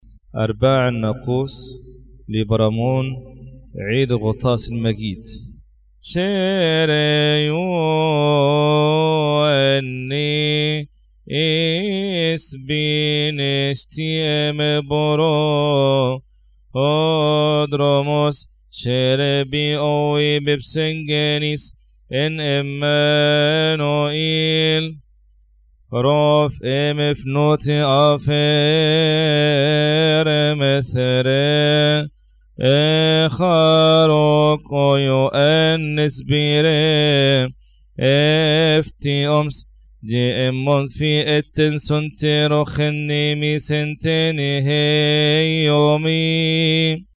مكتبة الألحان